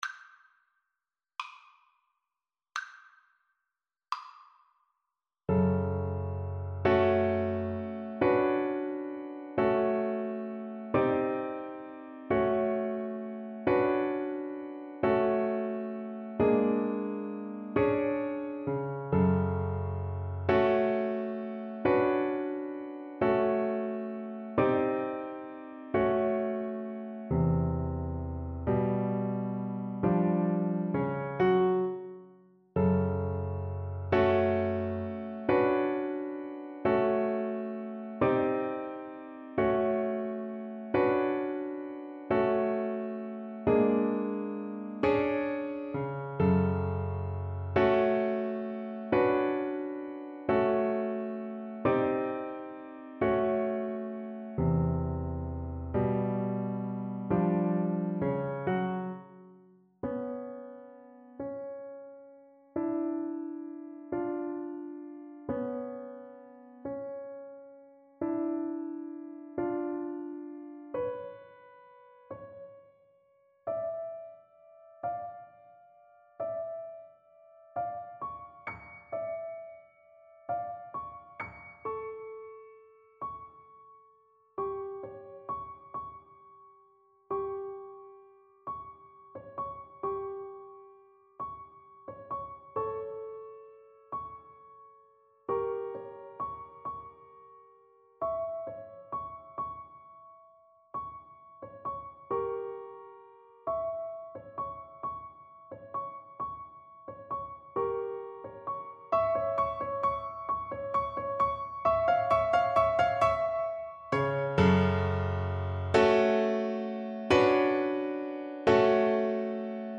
6/8 (View more 6/8 Music)
Ab4-A6
Classical (View more Classical Violin Music)
film (View more film Violin Music)